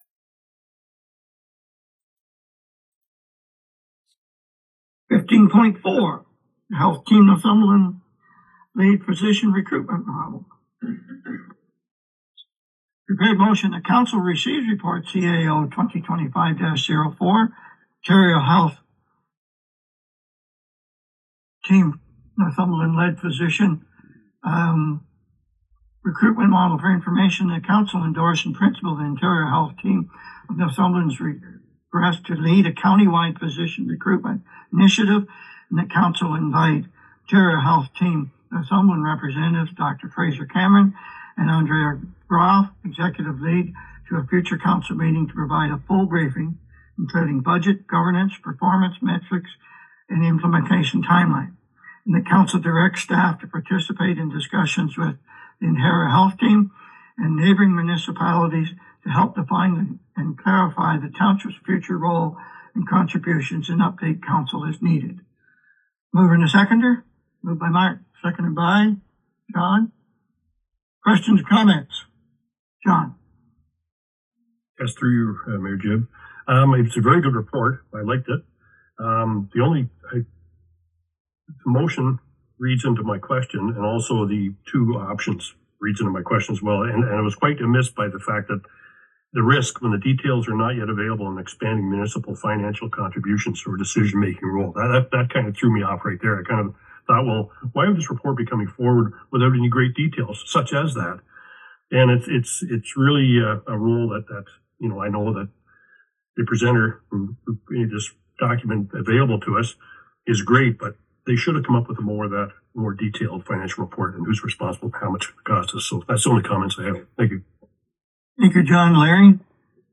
Listen to the Hamilton Township council debate and decision.